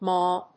/mˈɔː(米国英語), mɔ:(英国英語)/